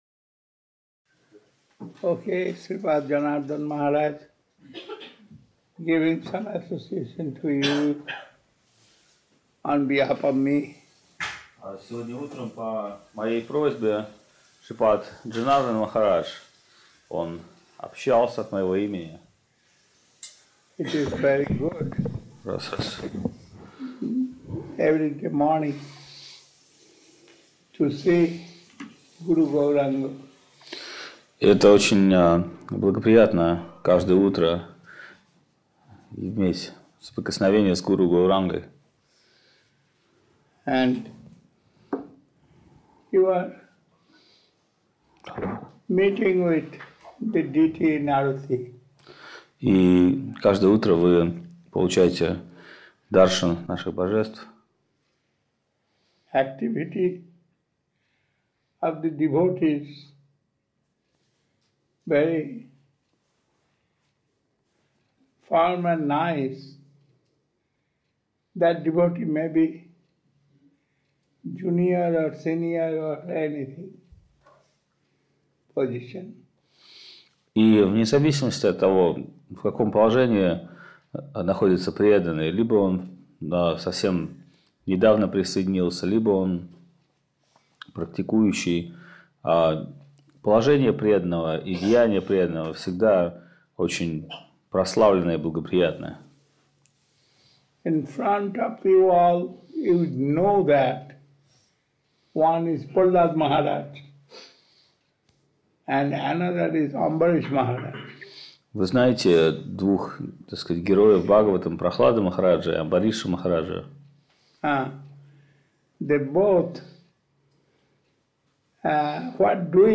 Даршаны 2006
Место: Лахтинский Центр Бхакти йоги